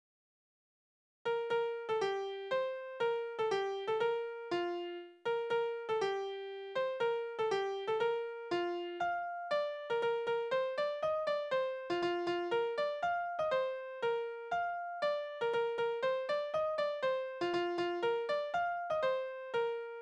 Wiegenlieder: Das Lämmchen im Holz
Tonart: B-Dur
Taktart: 3/4, 2/4
Tonumfang: Oktave
Besetzung: vokal
Anmerkung: zwei (nicht gekennzeichnete) Taktarten